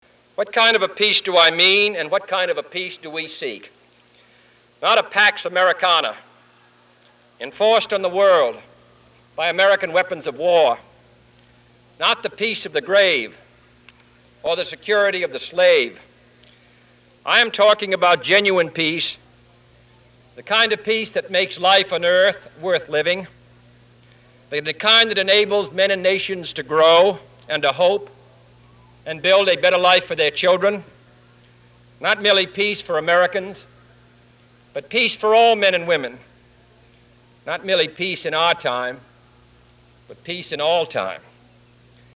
Commencement Address at American University, June 10, 1963